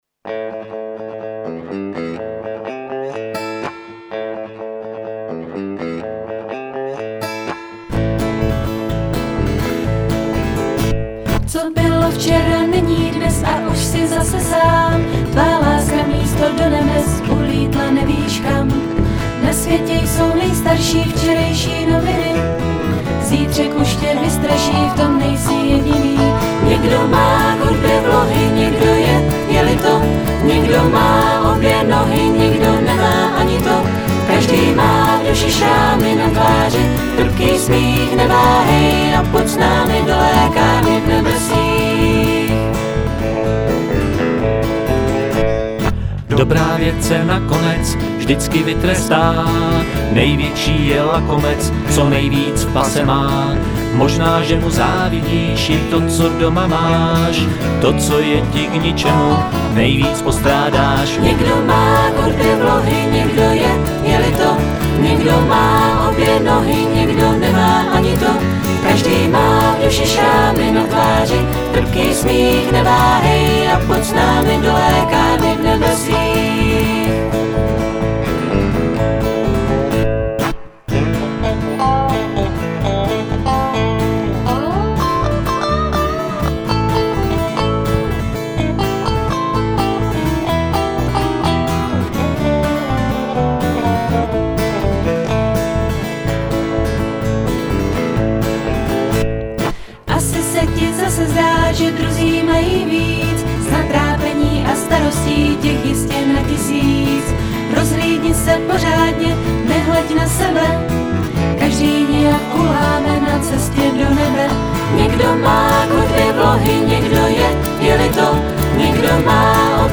Jen mi přijde, že zpěvy jsou moc rozhozené do stran.